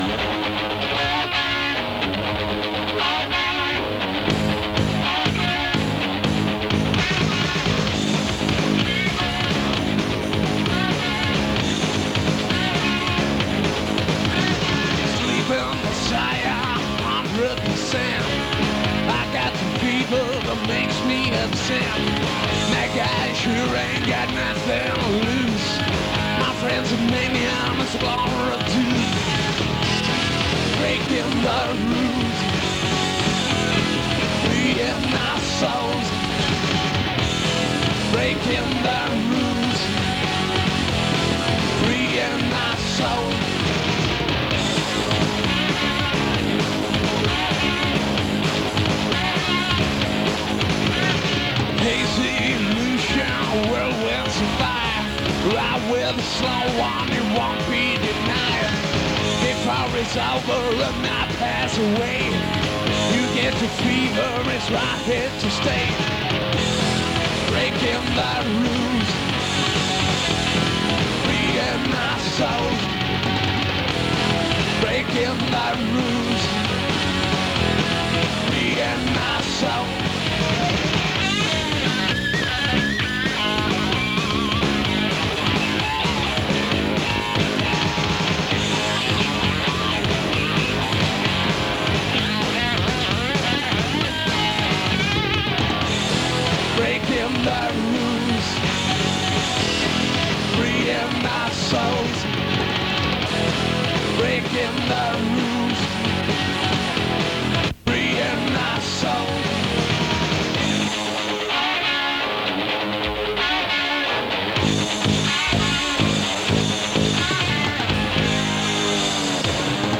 Spik-Johannes was one of my first bands I played live with and these live recordings I recently found on a cassette.